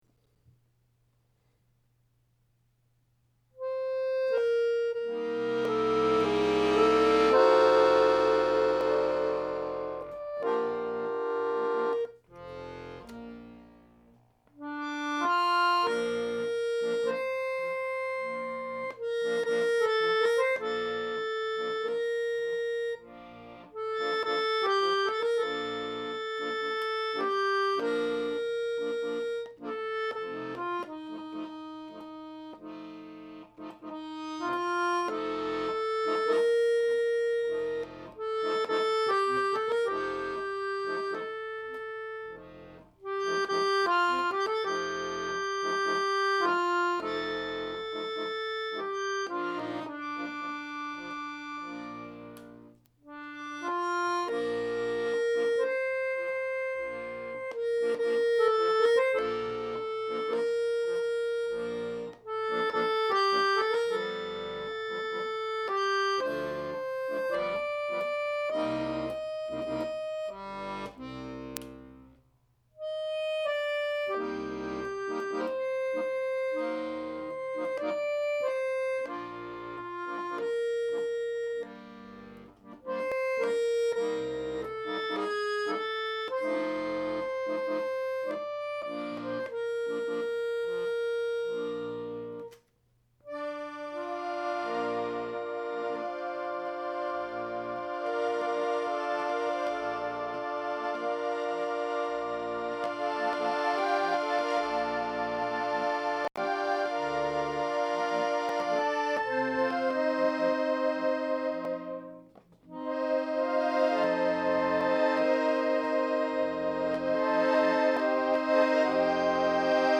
Nichts ist perfekt, aber das Lied kann man erkennen.